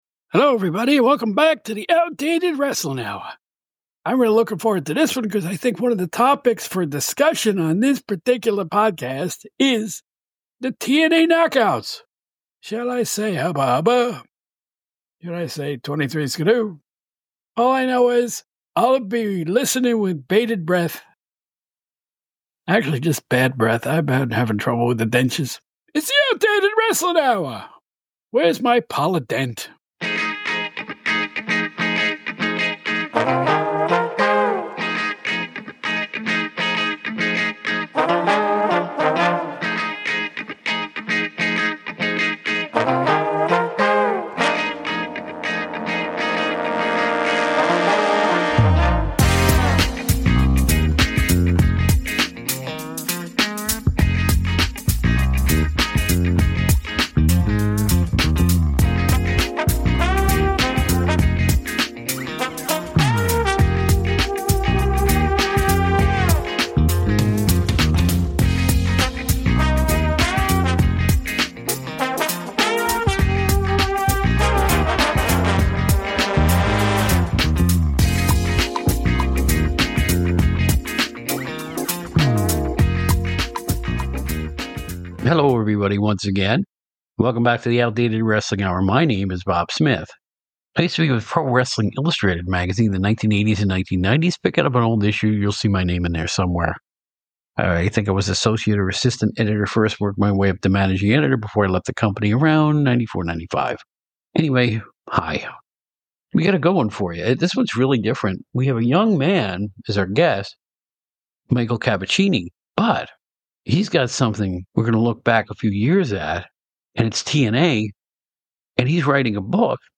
I had the pleasure of being interviewed